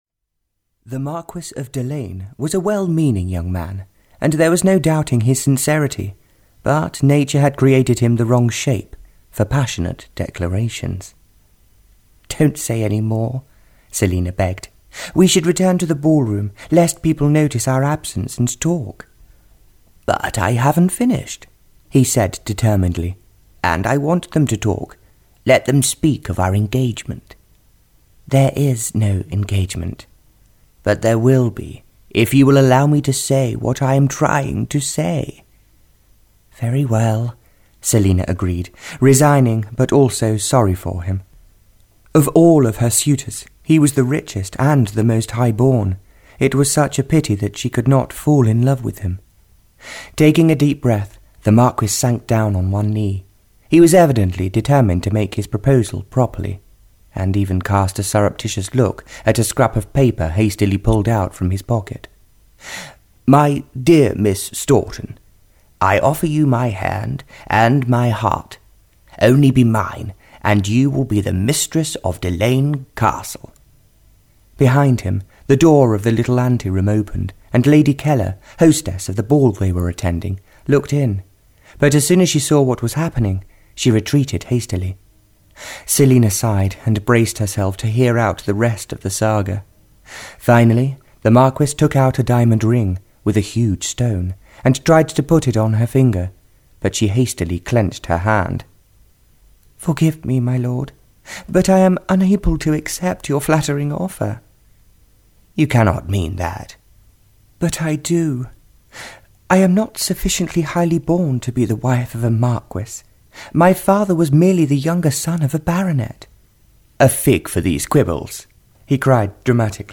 Audio knihaThey Sought Love (Barbara Cartland’s Pink Collection 24) (EN)
Ukázka z knihy